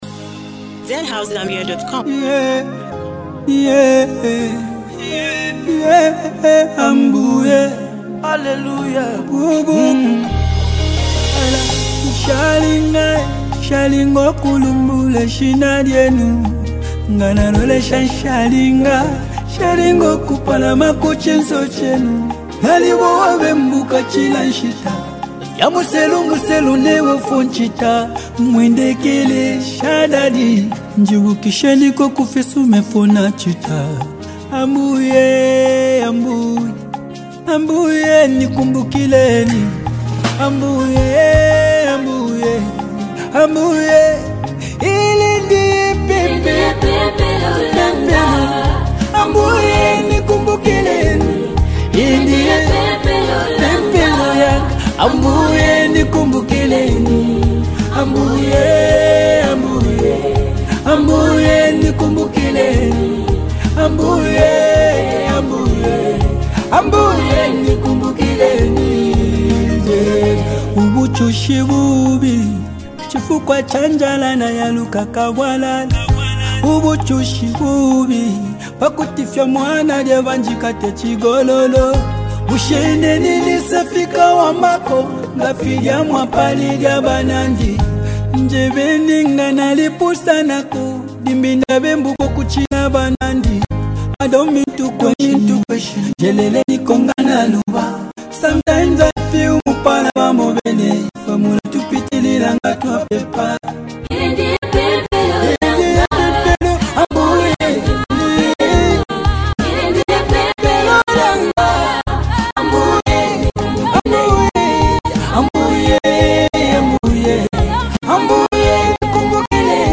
soulful masterpiece
heartfelt song filled with deep emotions and powerful vocals